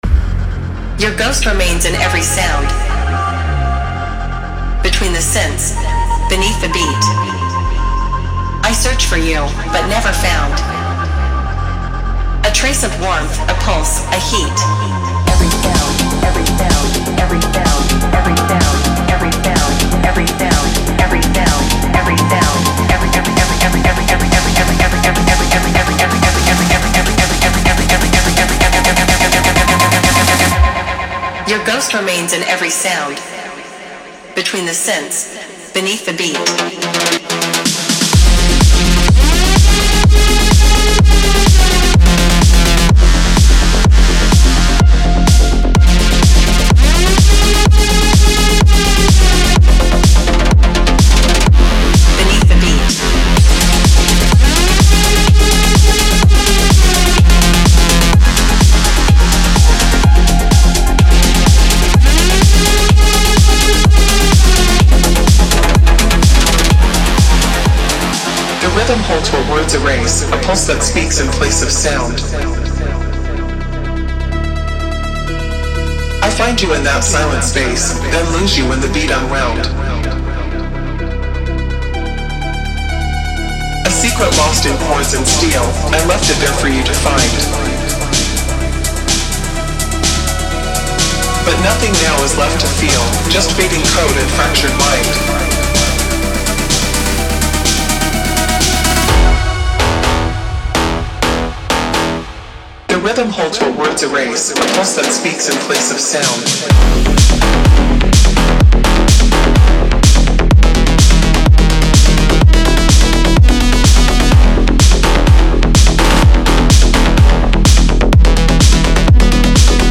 Genre:Melodic Techno
デモサウンドはコチラ↓
34 Vocal Loops
106 Synth Loops
31 Drum Loops